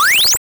Jump2.wav